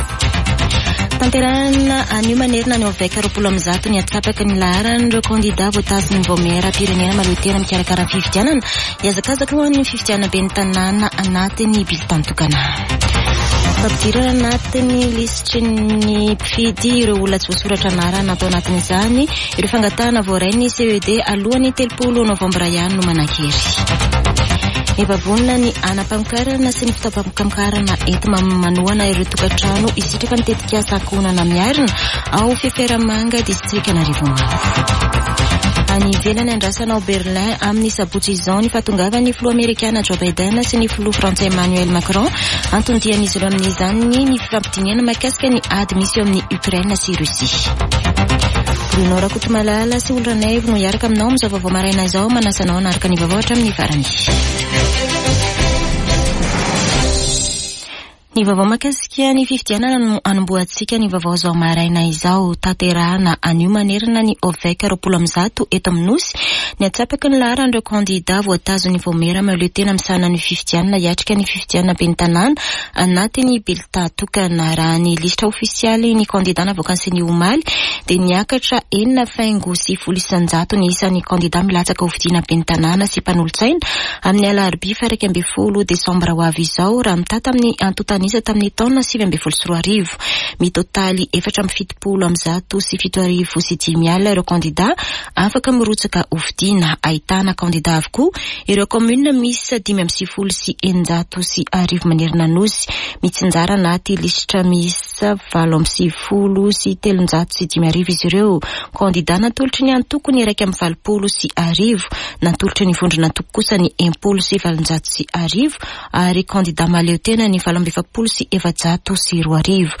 [Vaovao maraina] Alarobia 9 oktobra 2024